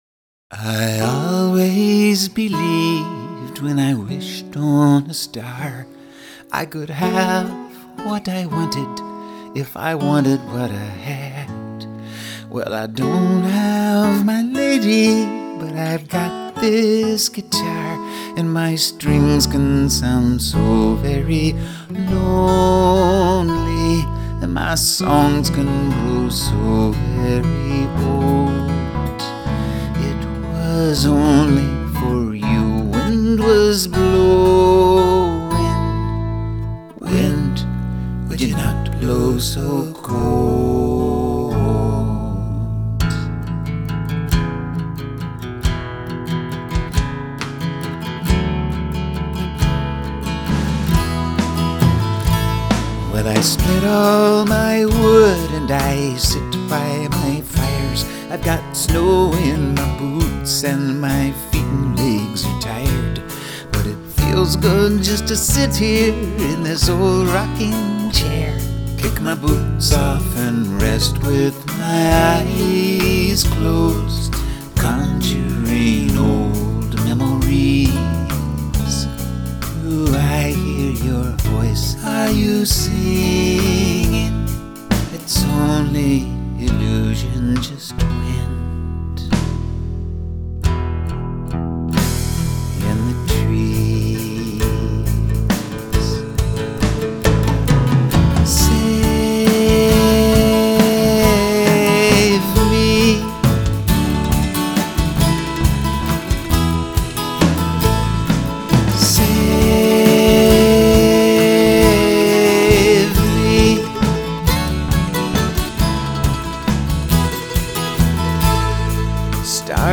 Oh... and all of the harmonies are me, myself and I again.